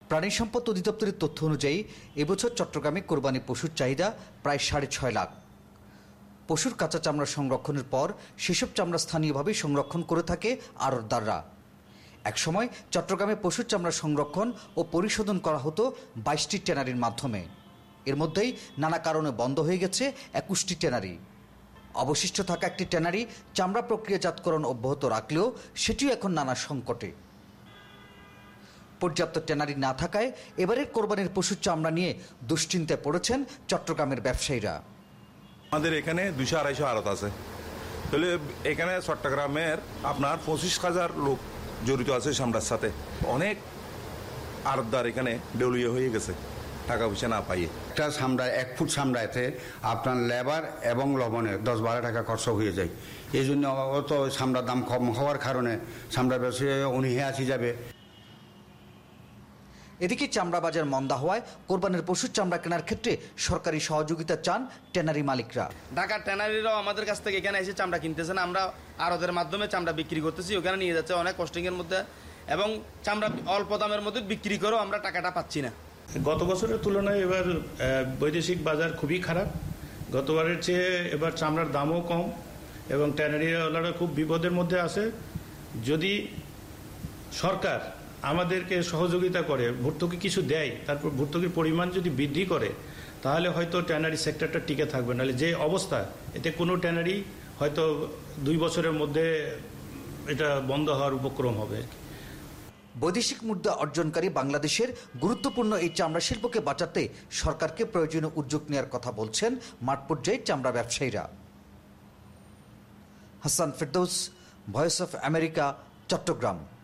সংবাদদাতা